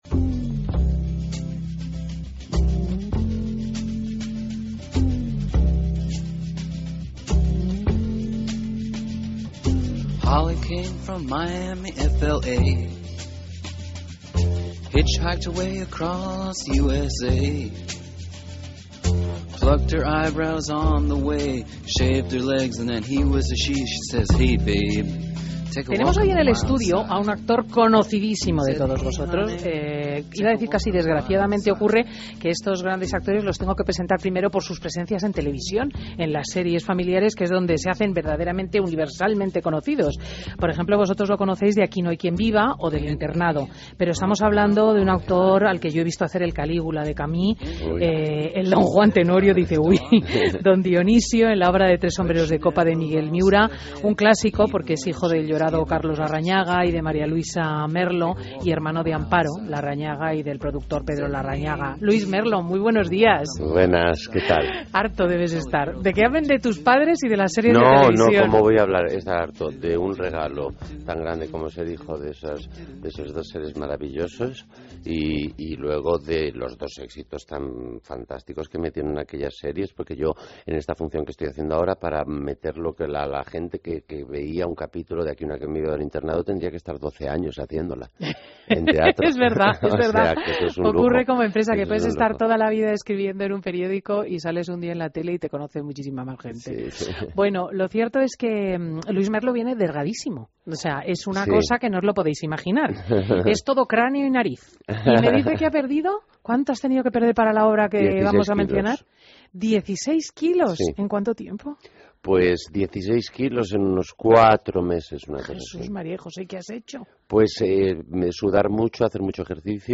Escucha la entrevista al actor Luis Merlo